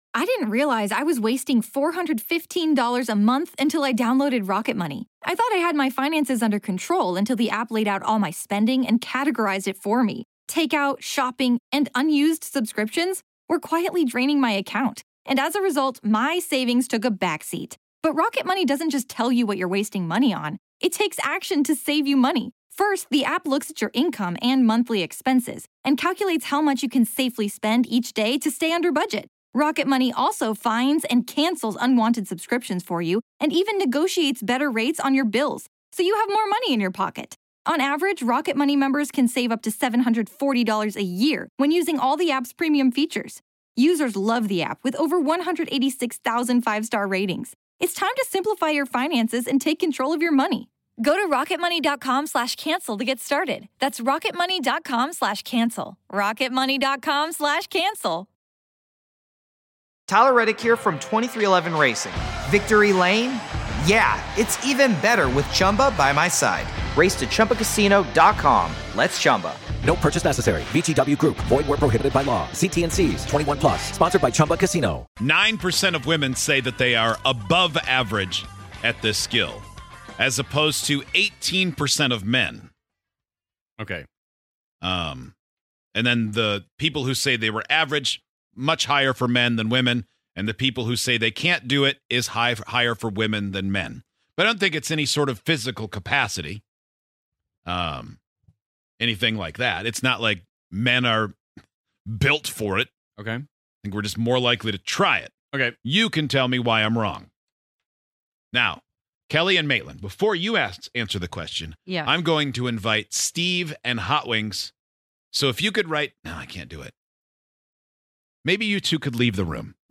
On today's show, would you say you're a really good whistler? Give this test a try and see if you're better than one show member who is surprisingly good at whistling!